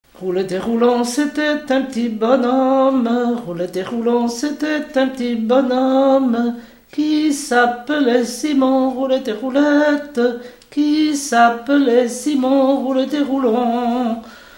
Genre laisse
Pièce musicale inédite